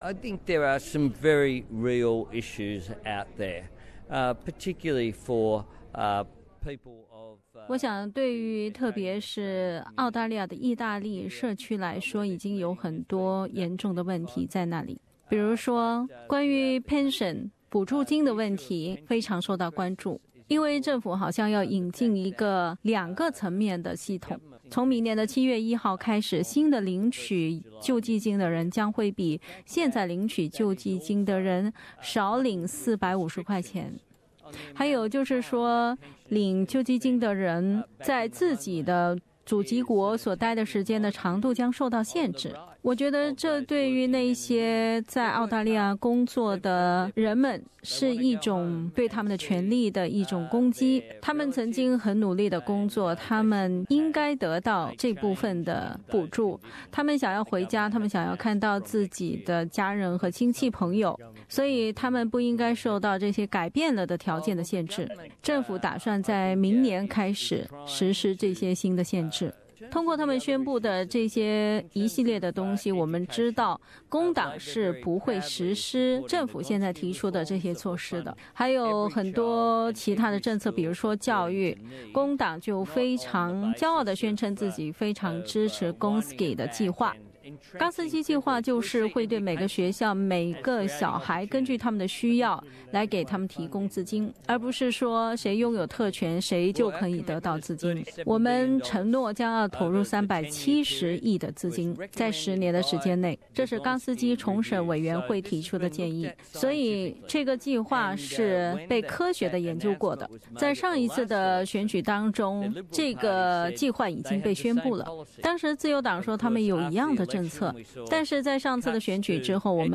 2016年的选举活动开始后不久，安东尼接受了SBS意大利节目组的采访，就意大利社区乃至民众关心的pension福利金、工党的教育、健康医疗、宽带网络政策等问题，和联盟党政府的政策就行了对比，并指出工党有很强的领导力，指责联盟党政府没有管理国家的具体计划，与社区脱节。
Former deputy PM Anthony Albanese (SBS Italian)